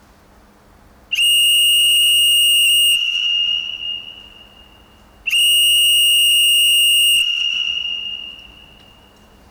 Wind Storm whistle
windstorm.wav